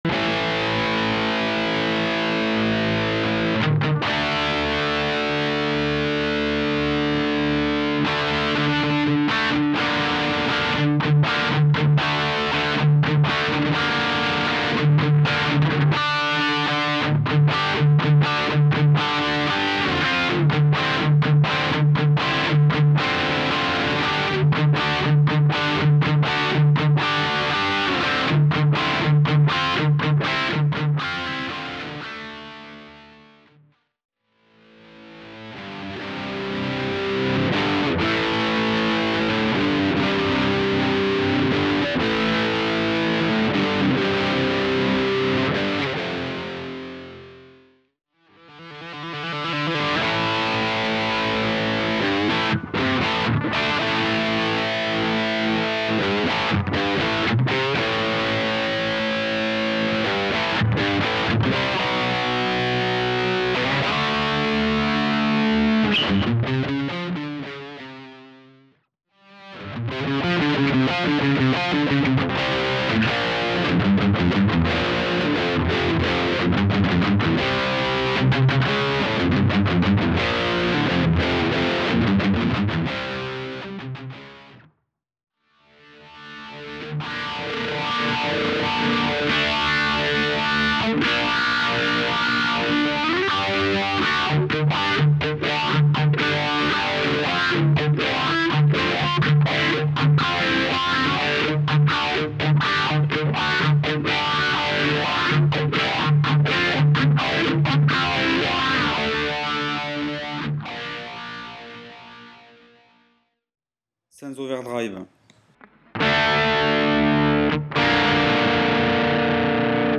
Sur l'épiphone, la gibson et la fender !!!
Alors le premier avec l'épiphone (annihilation-v) Je commence avec l'overdrive à ON et après sans...
Edit : euh je joue n'importe quoi ^^ c'est juste pour te donner le son de l'ampli !